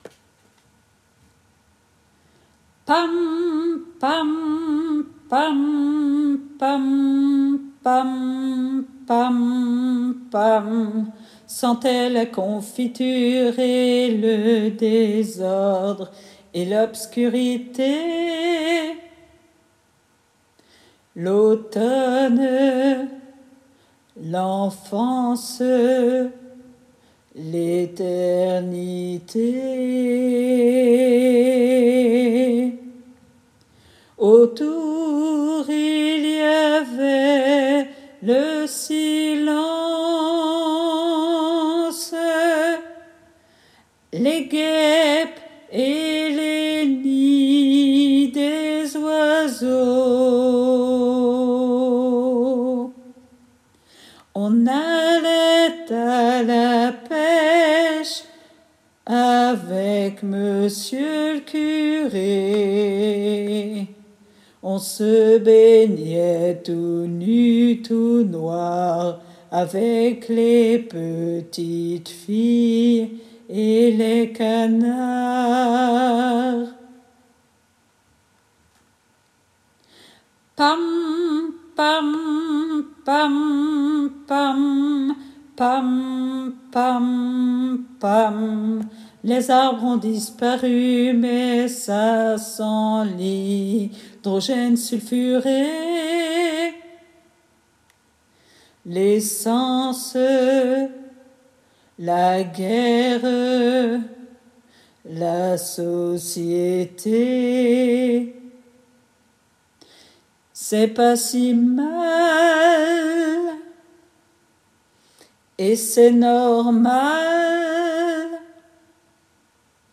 MP3 versions chantées
Basse